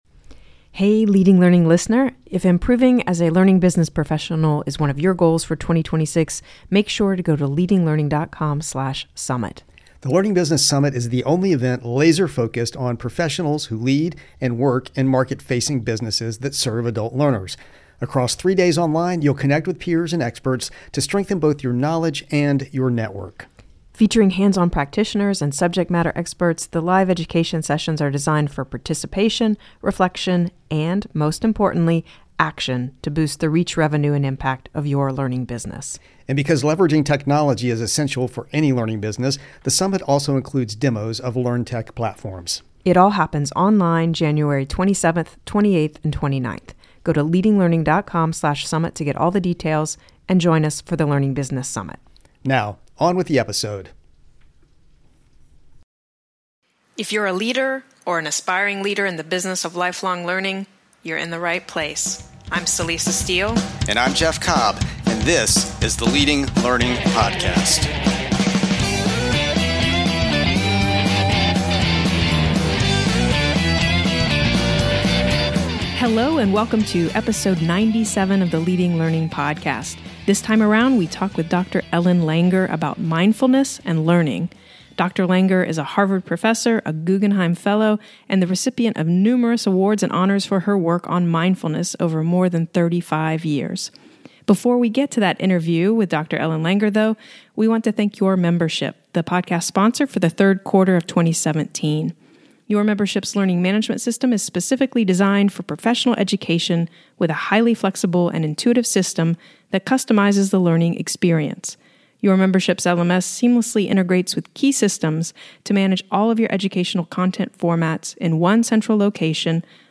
Note: We re-aired this insightful interview with Dr. Langer in December 2022.